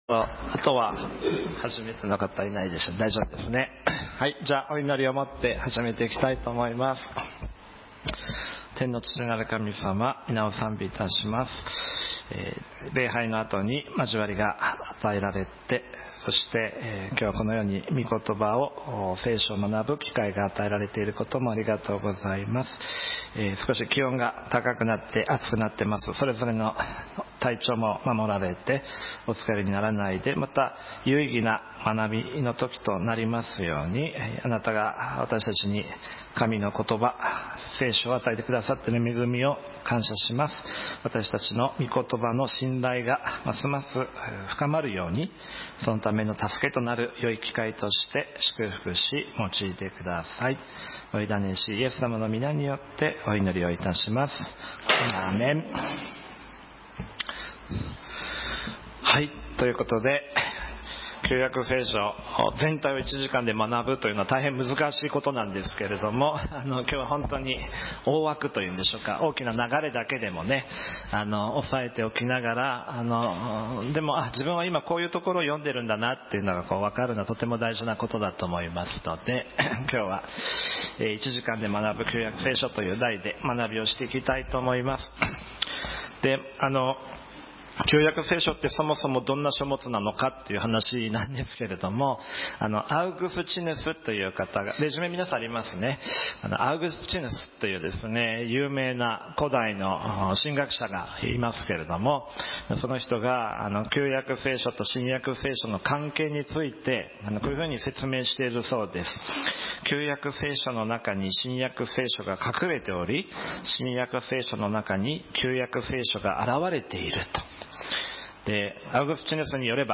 毎聖日ごとの礼拝メッセージを書き起こし、師の許可を得て掲載しております。